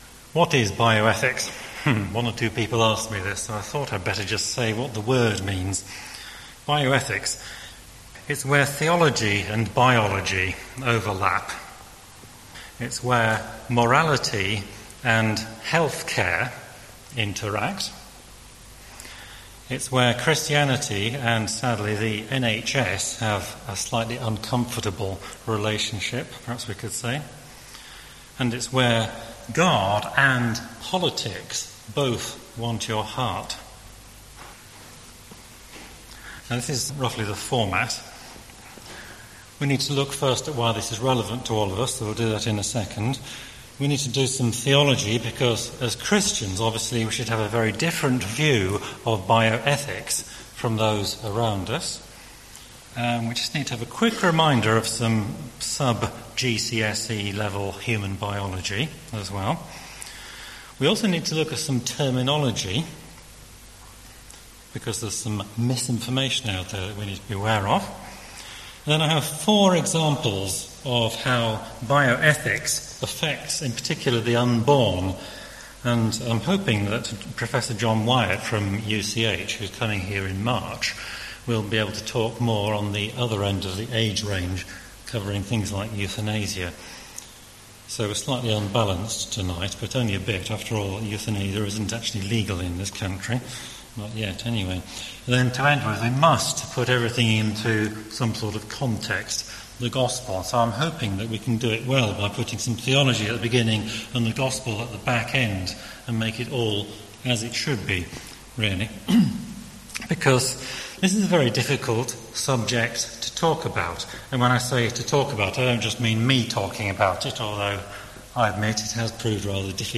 Simple Bioethics for Christians A 40 minute MP3 file containing the talk that goes with these slides . The talk covers bioethical issues at the early end of human life, i.e. not euthanasia, but the combined oral contraceptive "pill", the morning after pill (MAP) etc. How Can I Become a Christian?
bioethics_talk_with_slides.mp3